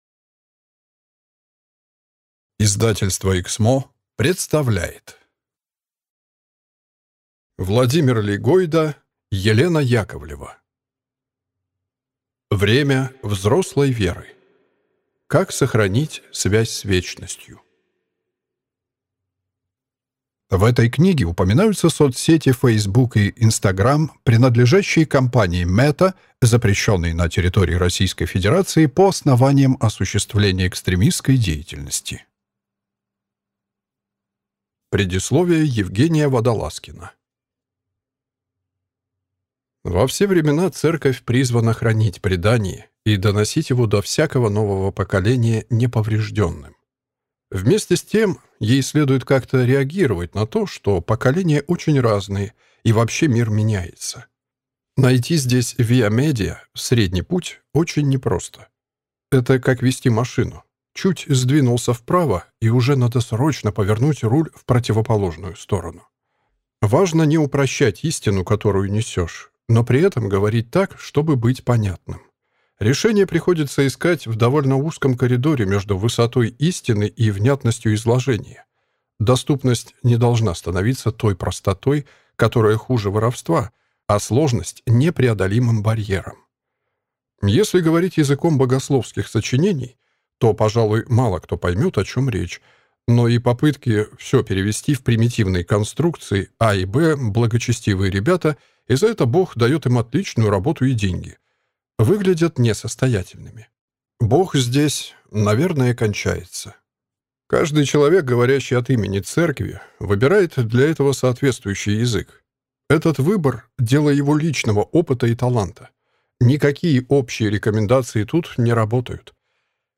Аудиокнига Время взрослой веры. Как сохранить связь с вечностью | Библиотека аудиокниг